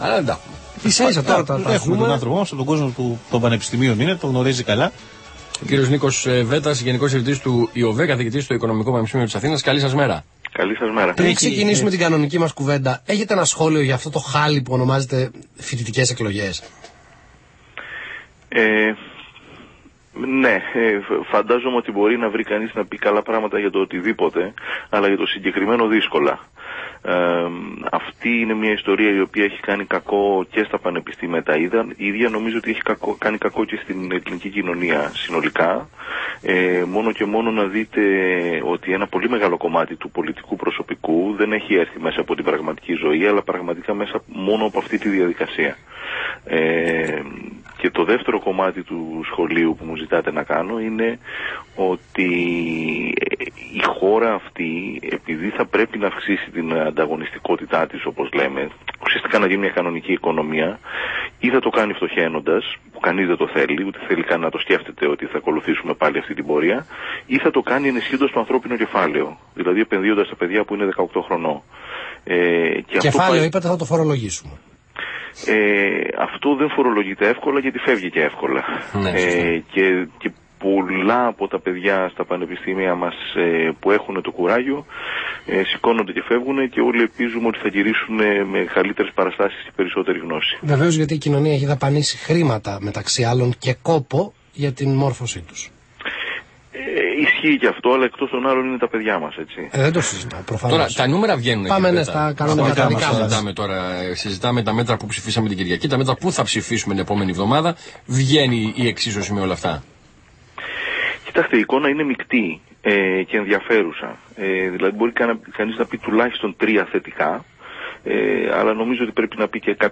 Συνέντευξη στον Αθήνα 9,84